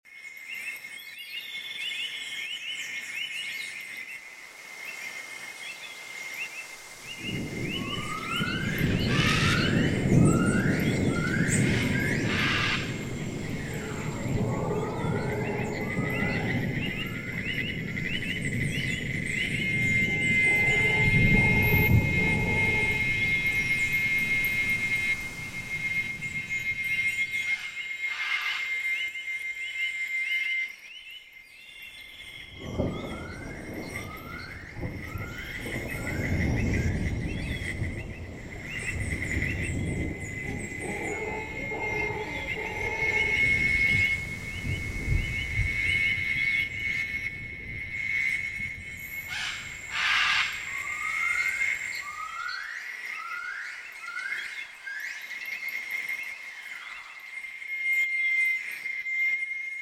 JungleAmbienceDayThunder.ogg